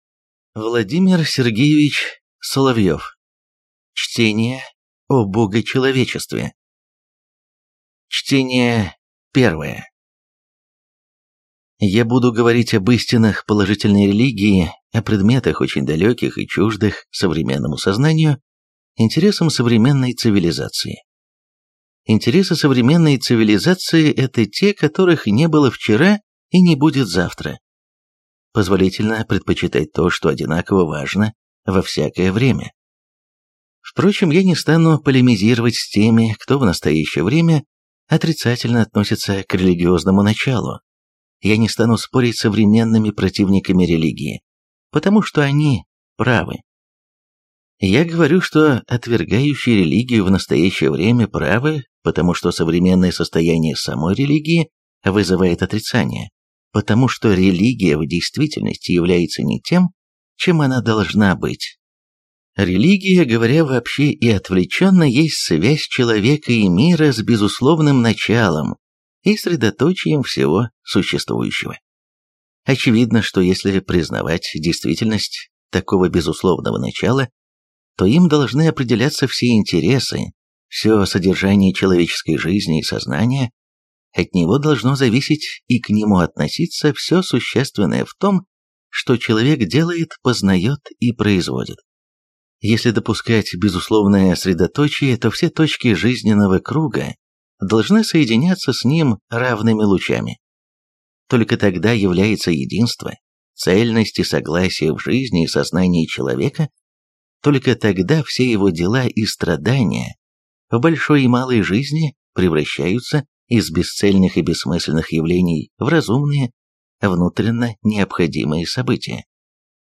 Аудиокнига Чтение о Богочеловечестве | Библиотека аудиокниг